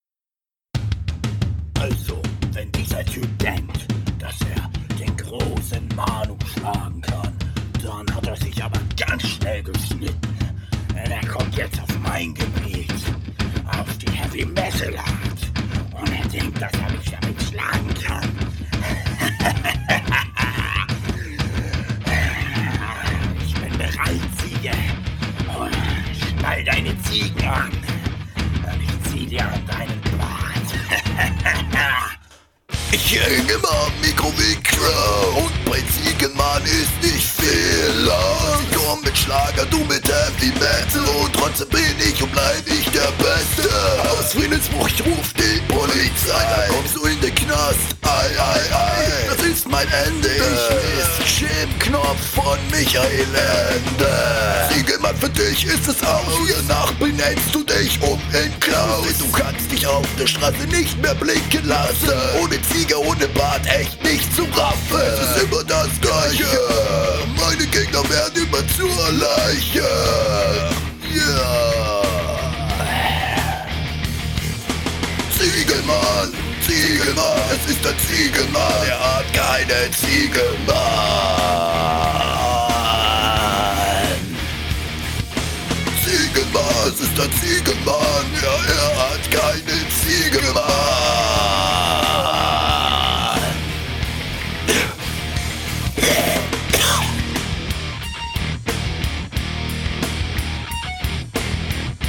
Kein Punkt gar kein Flow.